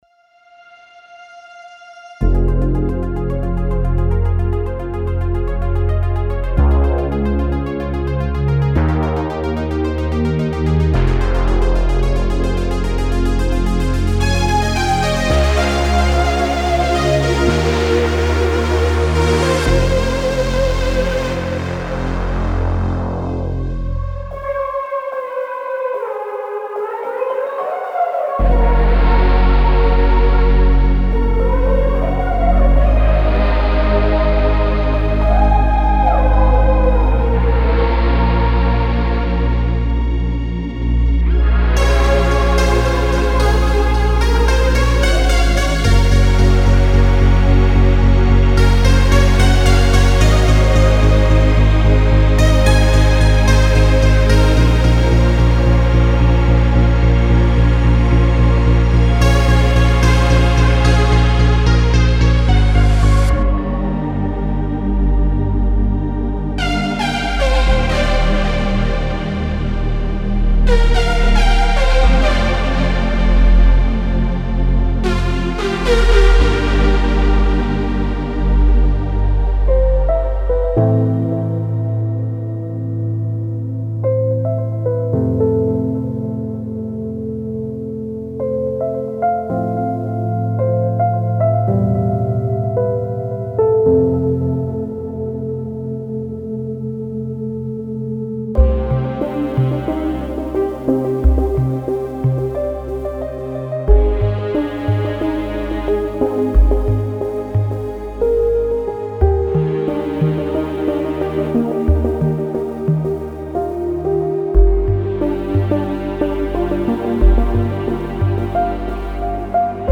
vintage sounds like Stranger Things for Xfer Serum 2
• 80s-inspired soundtracks & retro scoring
• chillout, lofi & ambient productions
• melancholic pop & indie electronica
MP3 DEMO